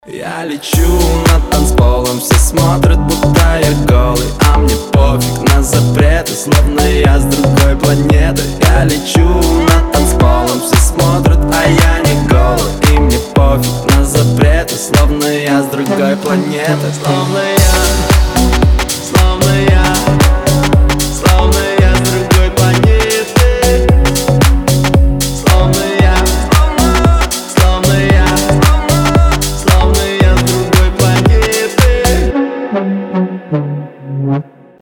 поп
ритмичные
dance